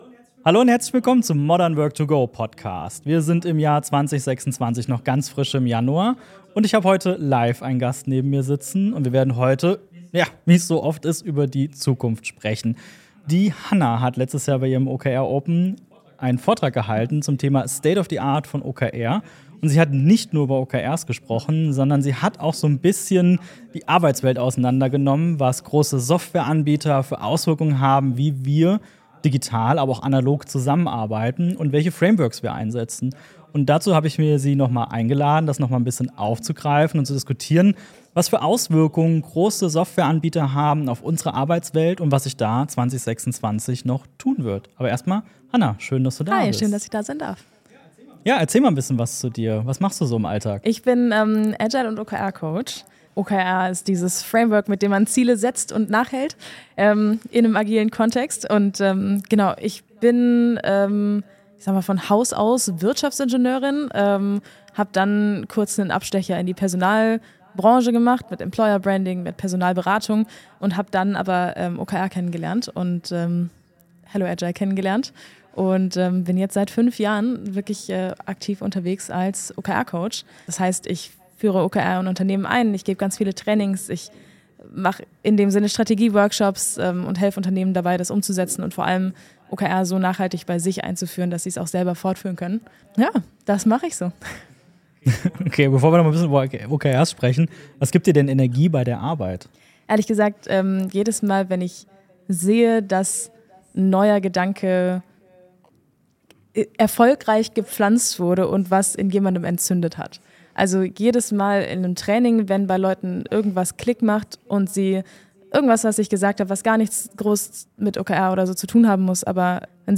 #93 Wirksame Ziele - State of the Art OKR - Interview